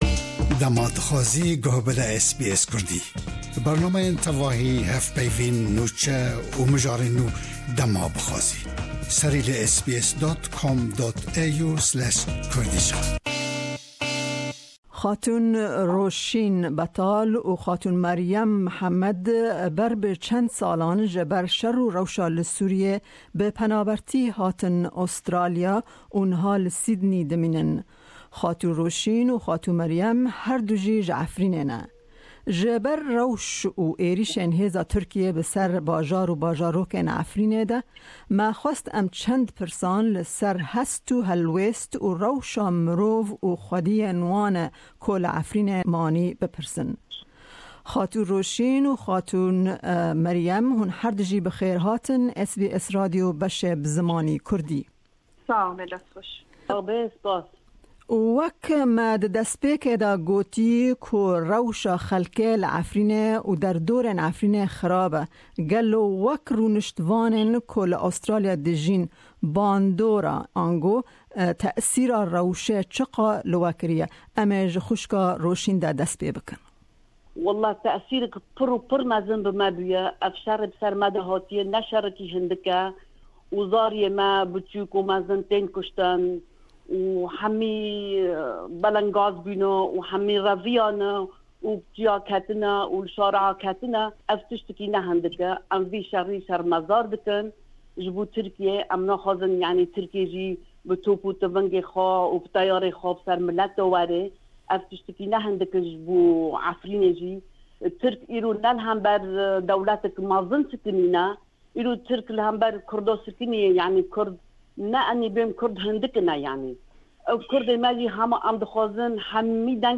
Ji ber rewsh û êrîshên hêza Tirkiyê bi ser bajar û bajarokên Efrînê de, me hevpeyvînek li ser hest û helwêst û rewsha mirov û xwediyên wan e ku li Efrînê manî pêk anî.